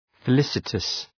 Shkrimi fonetik {fı’lısətəs}